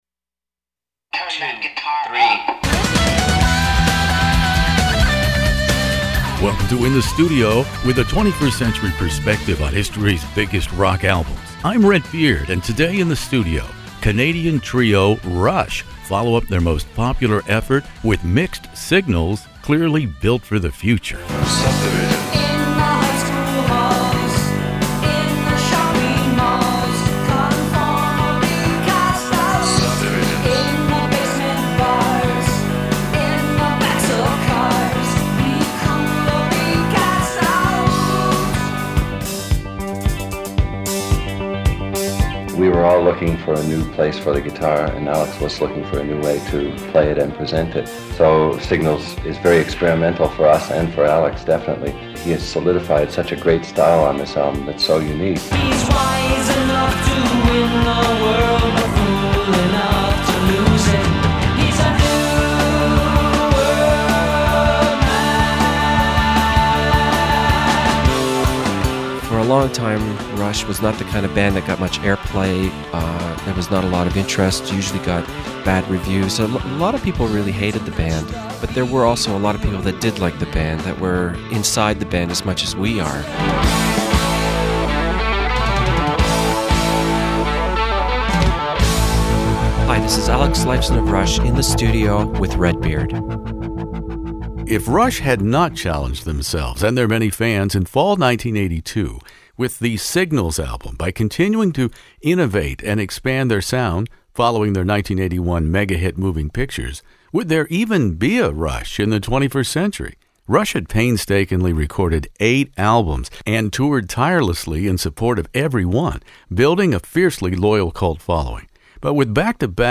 Rush "Signals" interview Geddy Lee, Alex Lifeson, the late Neil Peart
Geddy Lee, Alex Lifeson, and the late Neil Peart all weigh in for this classic rock interview.-